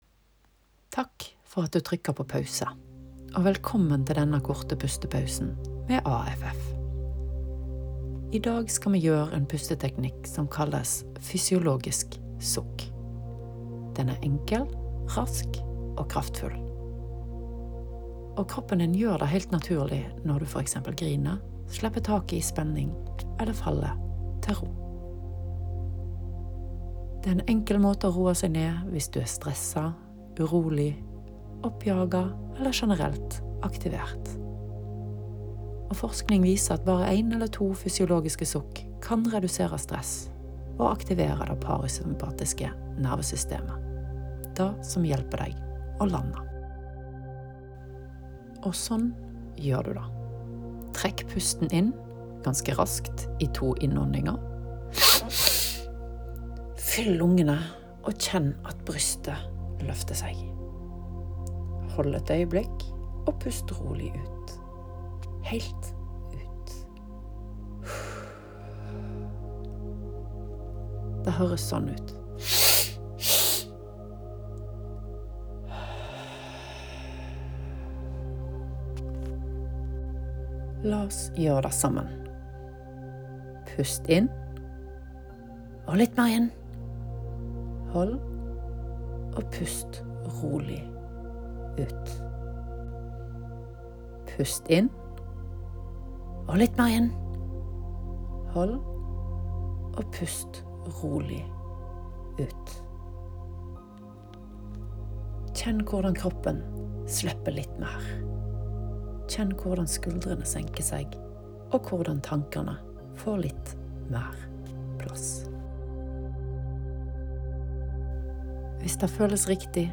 Her får du korte, konkrete øvelser for pust, avspenning og tilstedeværelse – uten mystikk, bare effekt.